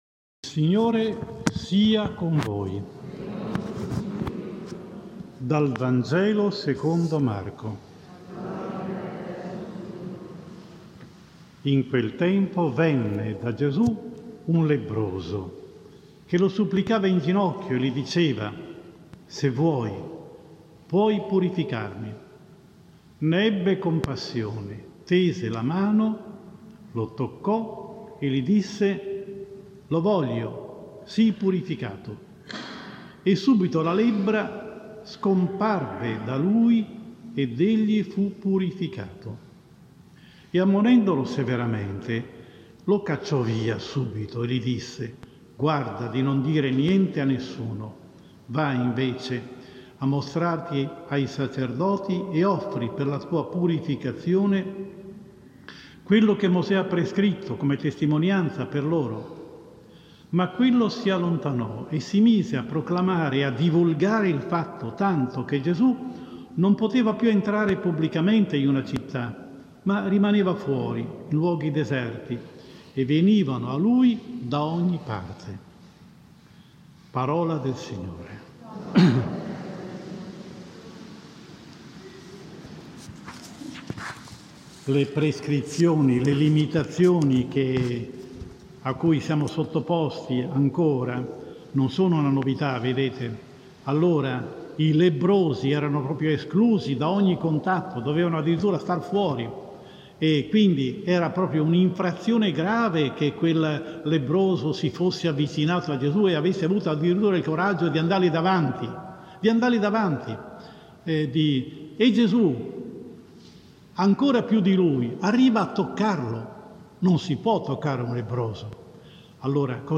14 febbraio 2021 VI DOMENICA del tempo ordinario (anno B): omelia